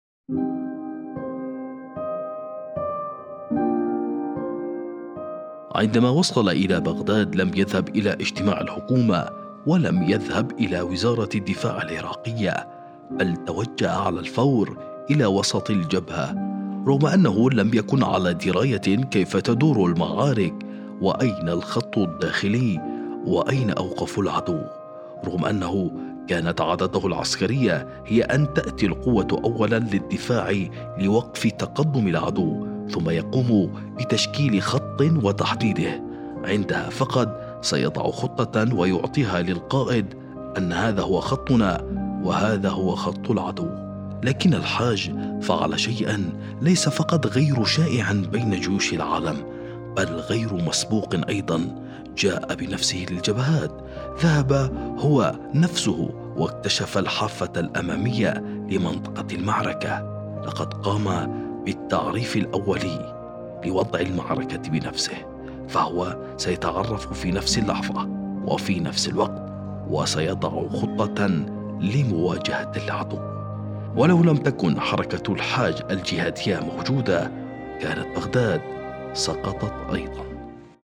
المصدر: برنامج قائد القلوب التلفزيوني المذاع على شبكة محافظة قم